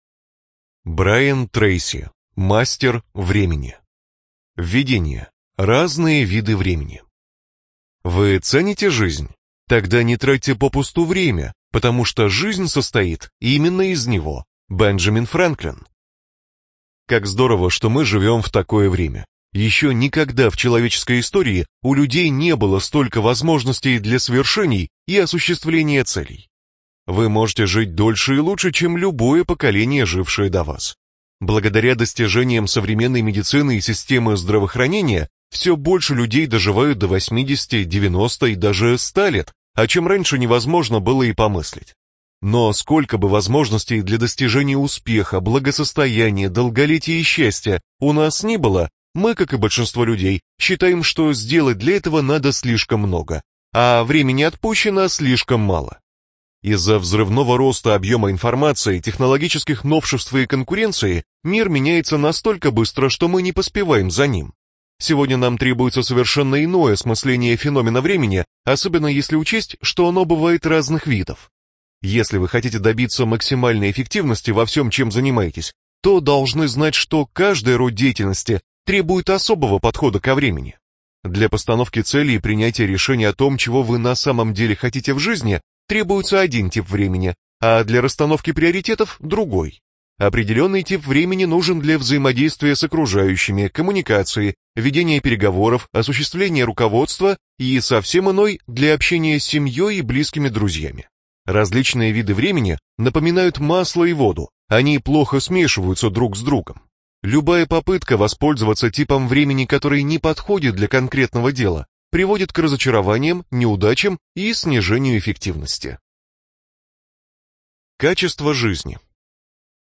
ЖанрПсихология